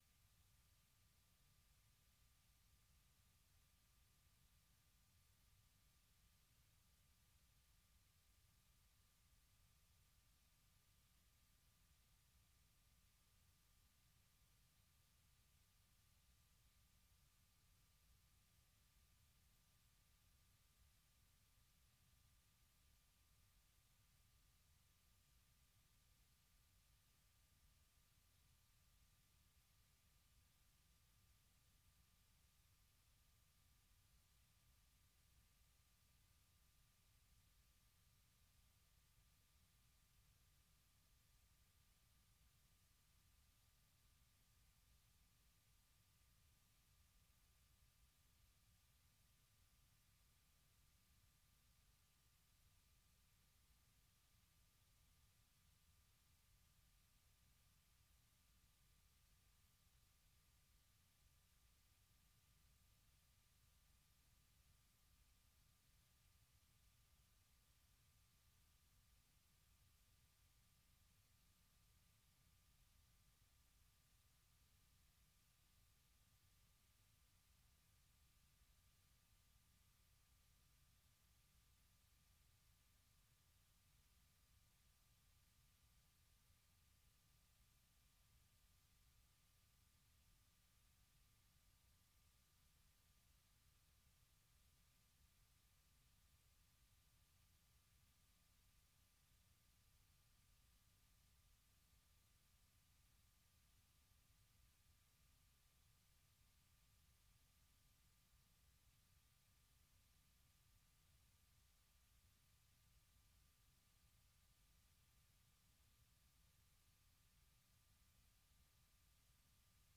Musique - Soul USA